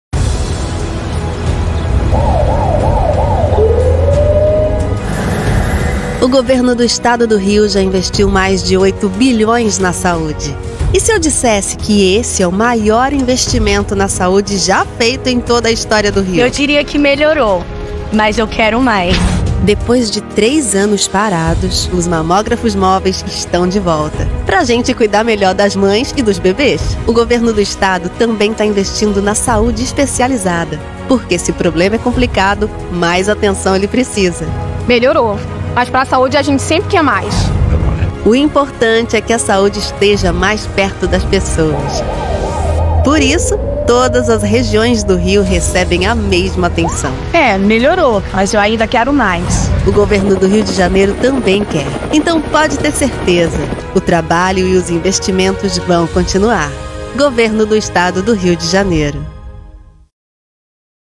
Female
Approachable, Assured, Bright, Cheeky, Conversational, Corporate, Deep, Friendly, Natural, Posh, Smooth, Warm, Young
My accent is considered neutral, with a soft memory of São Paulo prosody.
Warm, youthful, and highly professional, my voice delivers a natural and conversational performance with a neutral accent.
Microphone: AKG P220